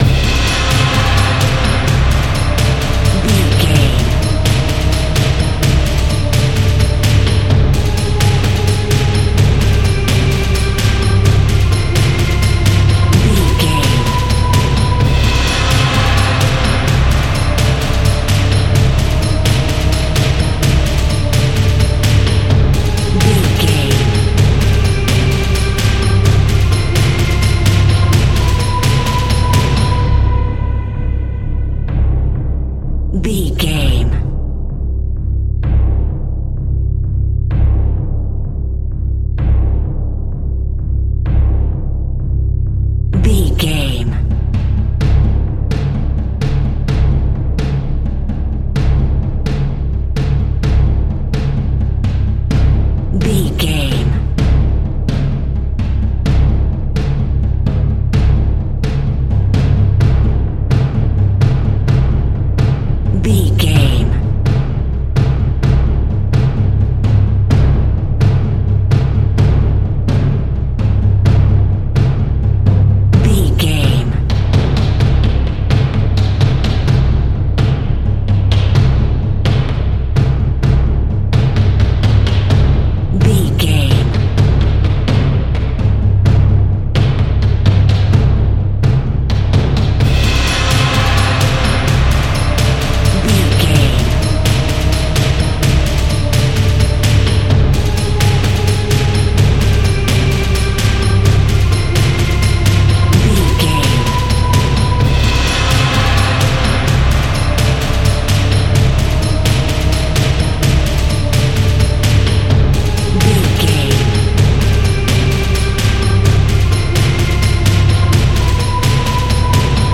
In-crescendo
Aeolian/Minor
Fast
scary
tension
ominous
dark
dramatic
eerie
driving
violin
cello
double bass
brass
drums
percussion
strings
viola
orchestral instruments